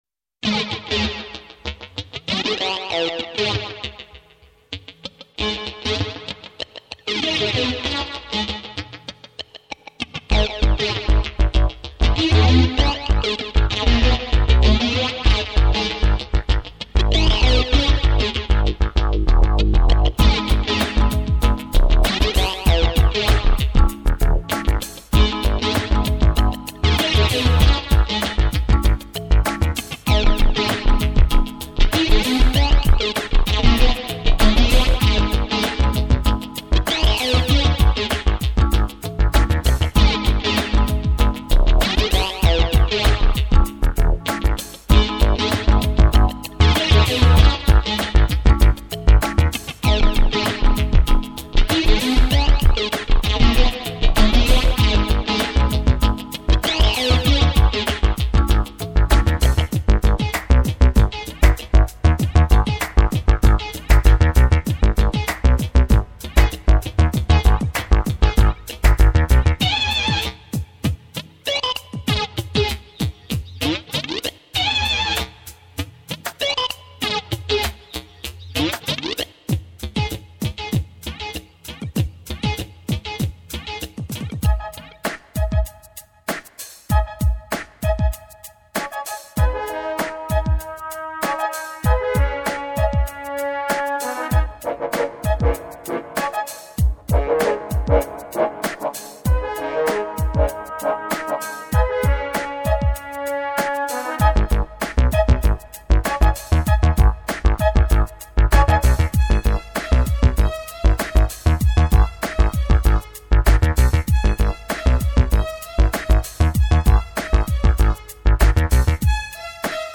De kan vel betegnes som værende hiphop.